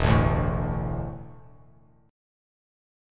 E7sus4 chord